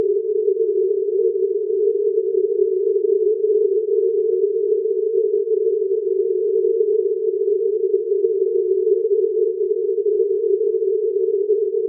Test signals B1 and B2 use out-of-phase probe tones with the same frequency and probe levels of 9 dB and 6 dB below the levels of the masker, respectively. The masker noise is presented in-phase in all cases, despite the sometimes startling spatial effects arising from masking release.